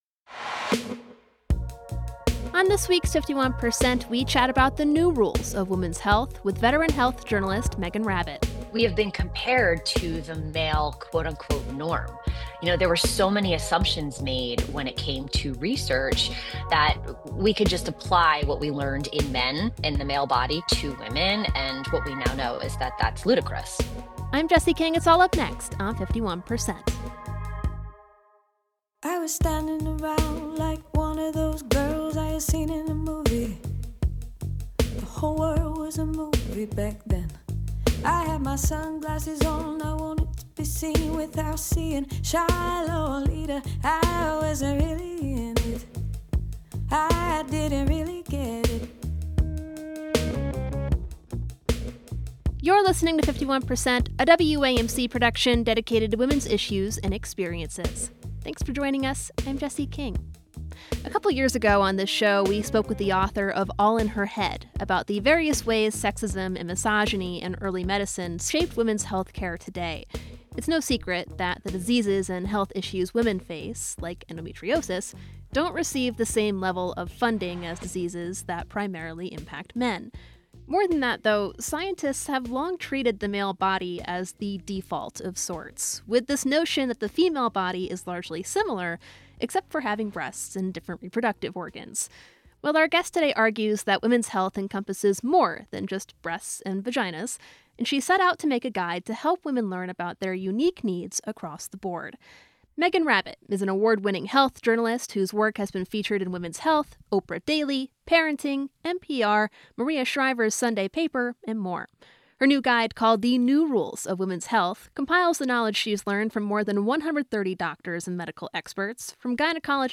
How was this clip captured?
51% is a national production of WAMC Northeast Public Radio in Albany, New York.